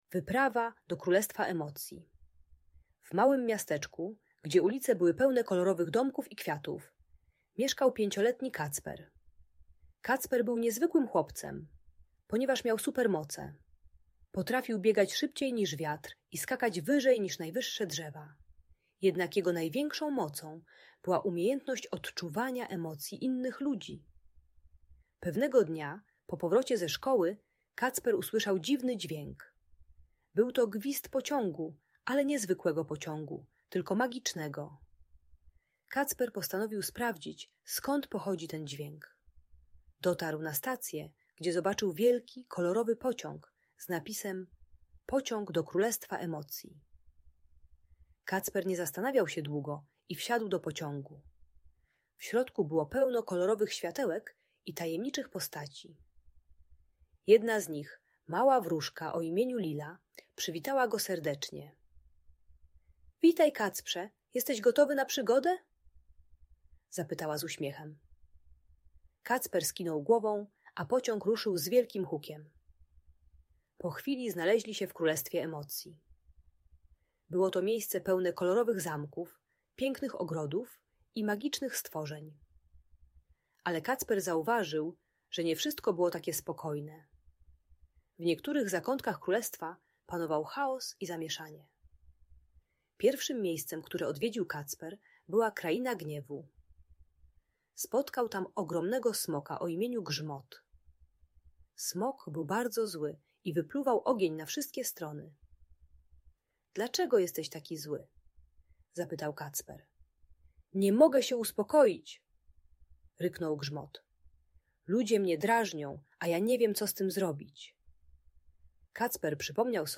Wyprawa do Królestwa Emocji - Audiobajka